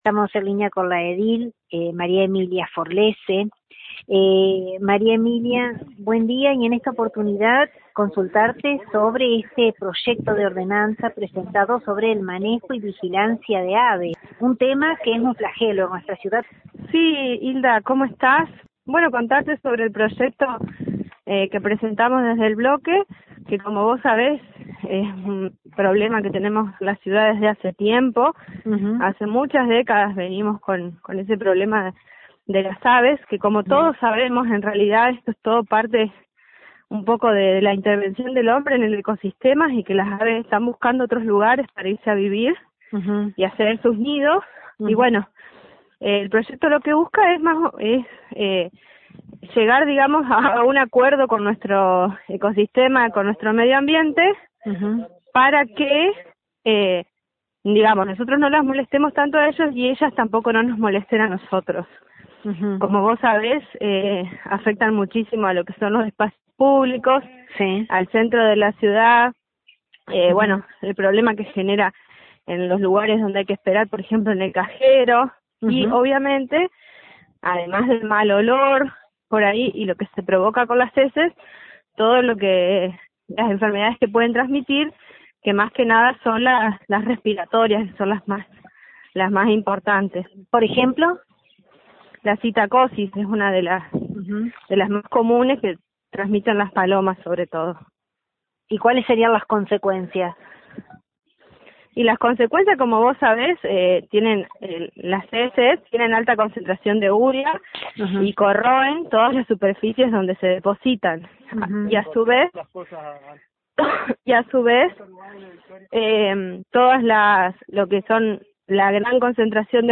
Desde LT39 NOTICIAS, dialogamos con la edil María Emilia Forlese, integrante del mencionado bloque; quien hizo hincapié en que el objetivo es lograr una buena relación de convivencia entre el ecosistema, medioambiente, aves y ciudadaní en genera; amén del trabajo articulado que realizan entre el ejecutivo local y la Secretaría de Salud y Medioambiente.